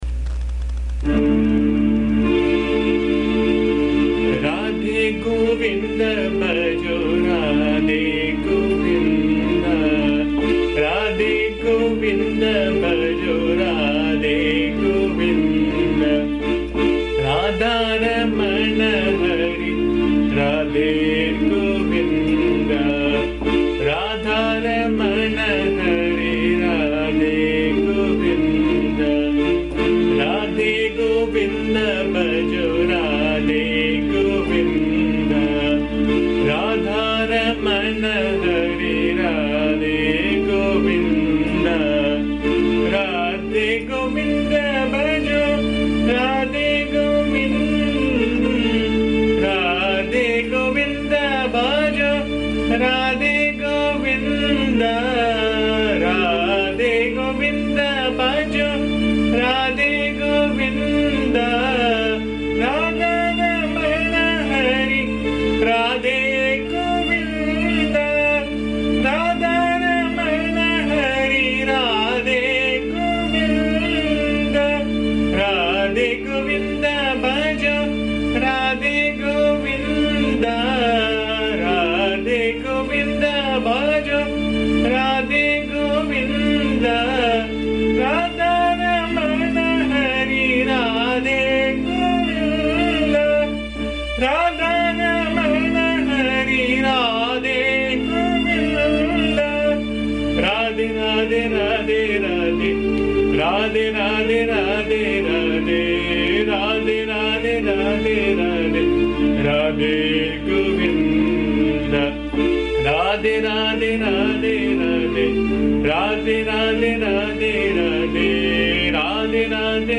AMMA's bhajan song View previous songs
Radhe govinda bhajo radhe govinda Radha ramana hari radhe govinda Let me sing praises of Krishna along with Radha Let me sing the praises of Vishnu who is the beloved of Radha This is a simple song singing the names of Krishna along with his beloved Radha. It is set in the Raga Sindhu Bhairavi. The song has been recorded in my voice which can be found here .